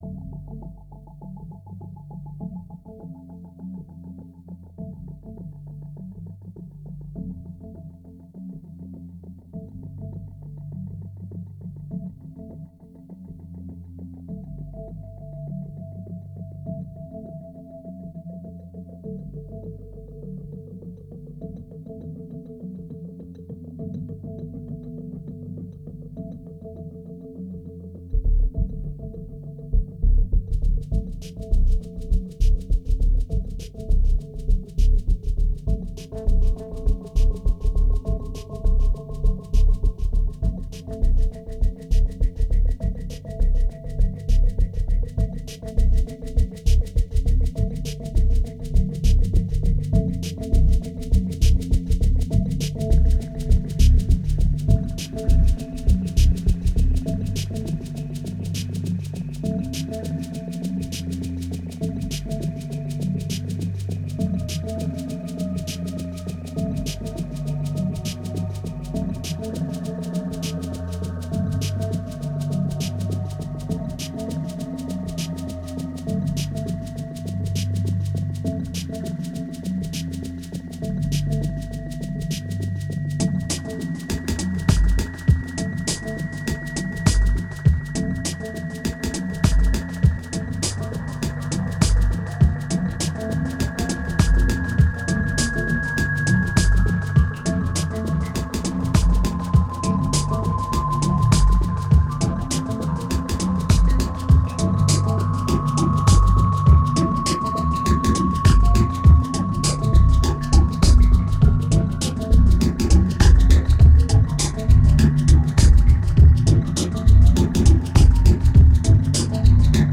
It's only autumn but the mood is already pretty somber.
1764📈 - -19%🤔 - 101BPM🔊 - 2010-10-03📅 - -249🌟